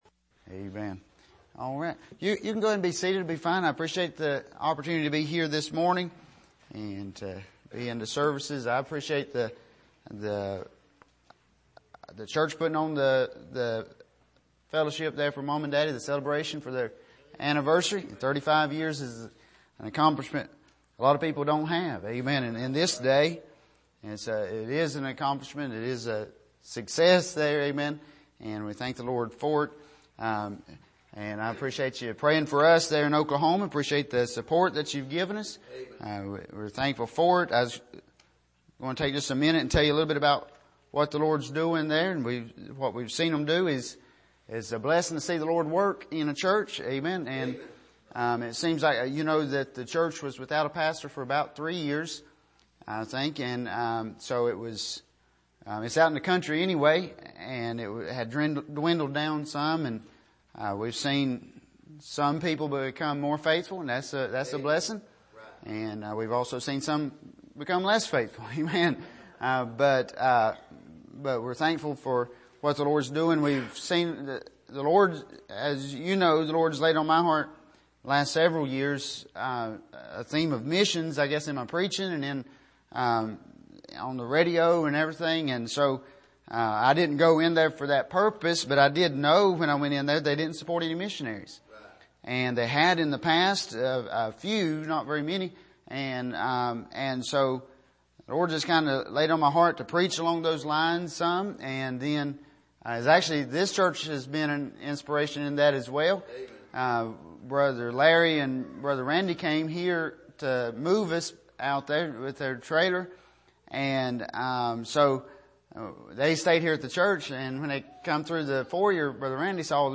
1 Peter 2:9-12 Service: Sunday Morning Reminders of Pilgrims and Strangers « The Path Of Folly And Death Part 2 Are You In?